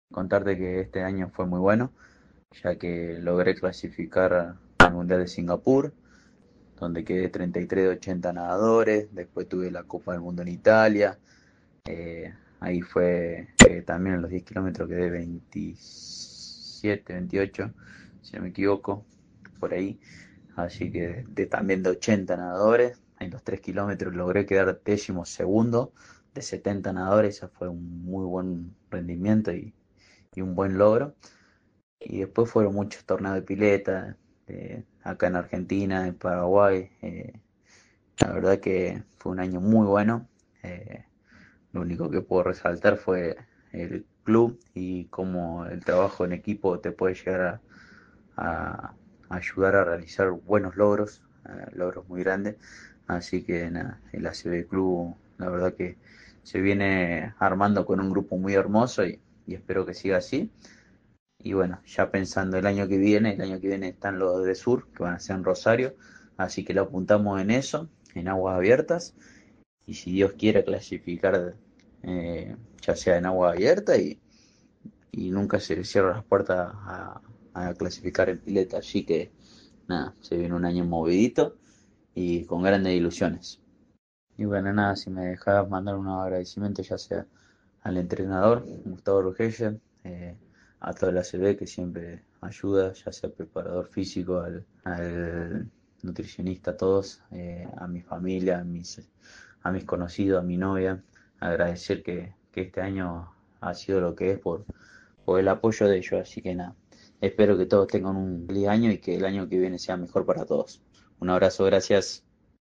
Audio nota